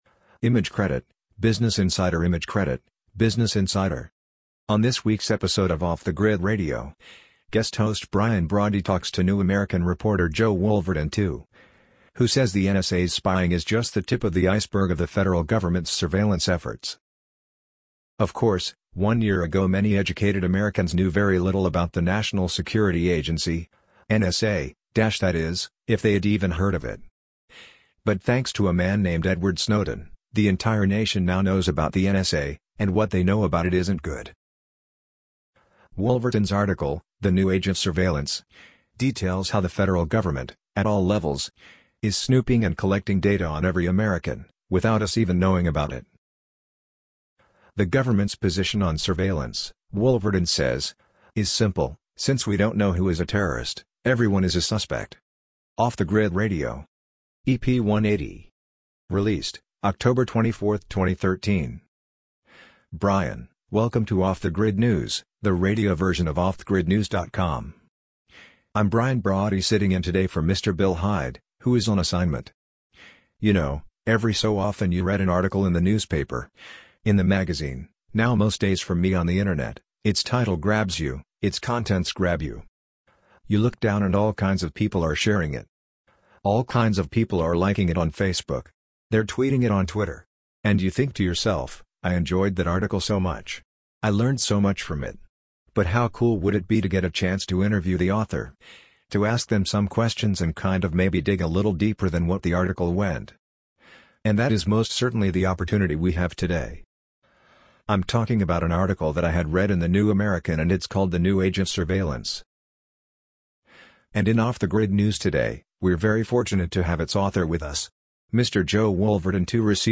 amazon_polly_39024.mp3